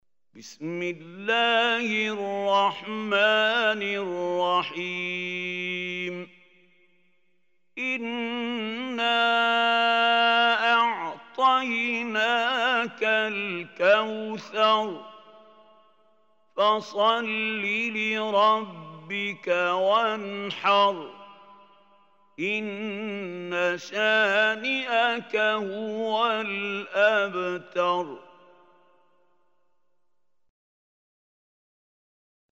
Surah Kausar MP3 Recitation by Mahmoud Khalil
Surah Kausar is 108 surah of Holy Quran. Listen or play online mp3 tilawat / recitation in arabic in the beautiful voice of Sheikh Mahmoud Khalil Hussary.
108-surah-kausar.mp3